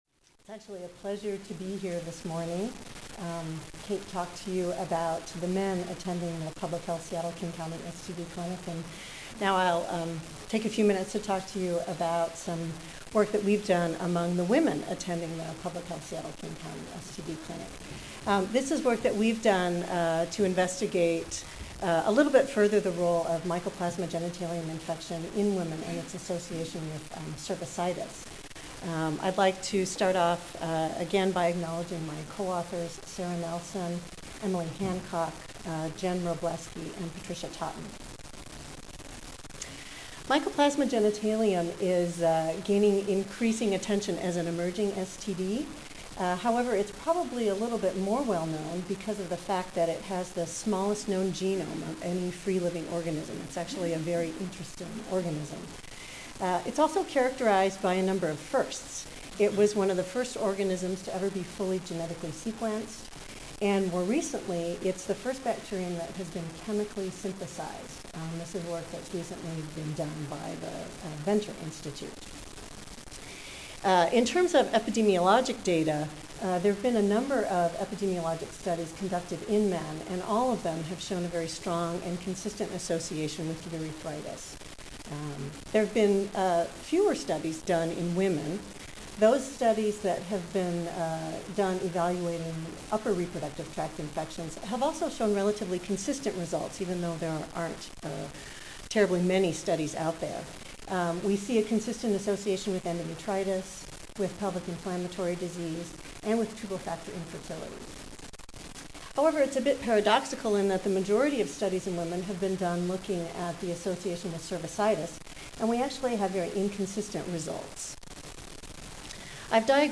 2008 National STD Prevention Conference: Clinical Syndromes and STD Prevention
Recorded presentation